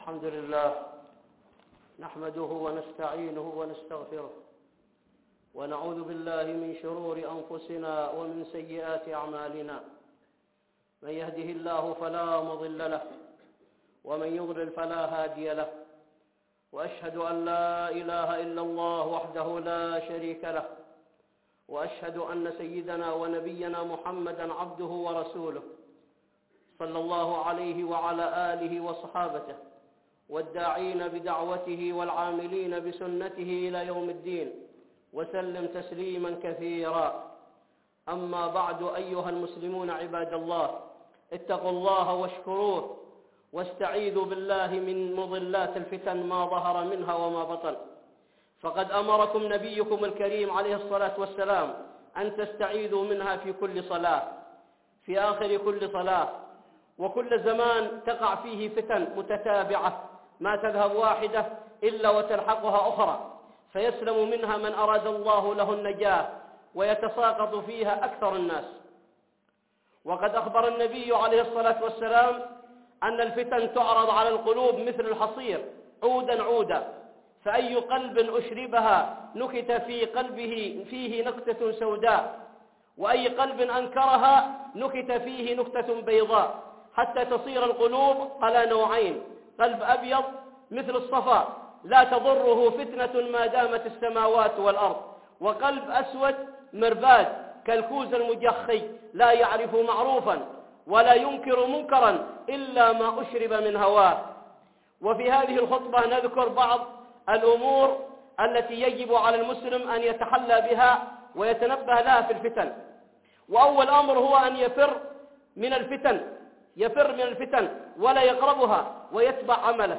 (خطبة جمعة) الفتن ودعوة أهل السنة